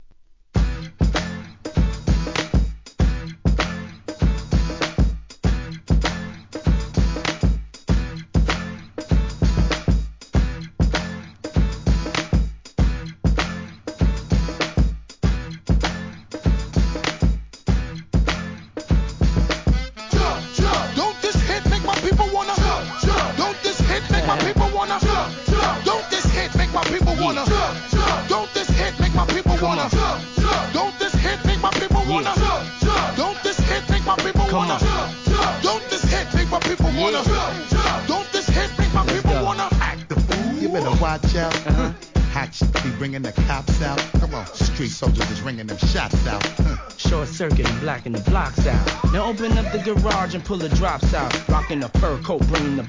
HIP HOP/REGGARE/SOUL/FUNK/HOUSE/
HIP HOP/R&B